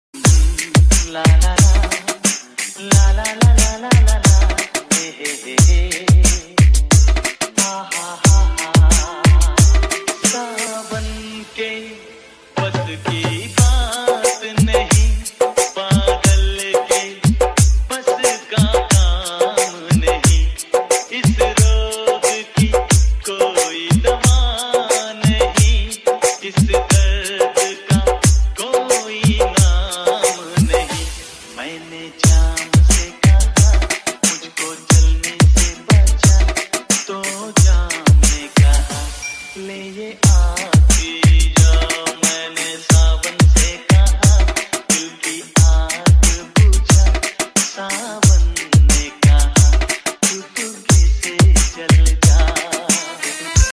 Light Remix